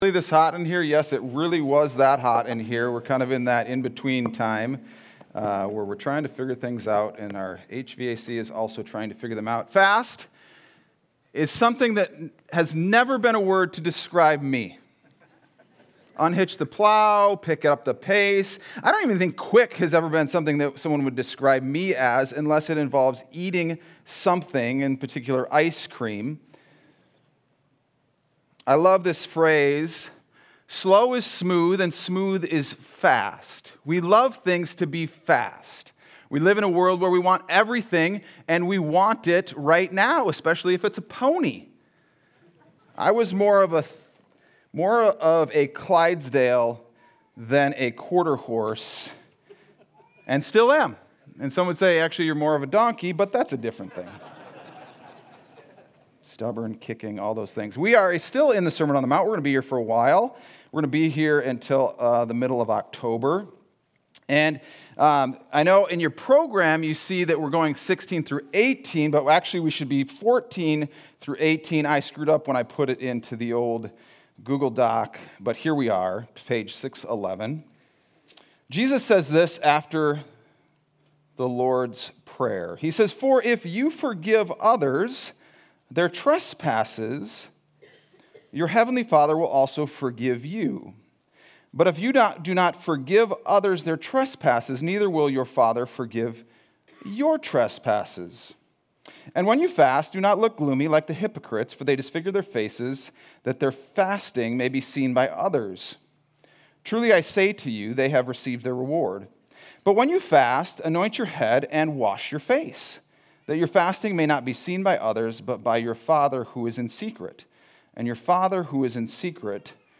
Sunday Sermon: 9-7-25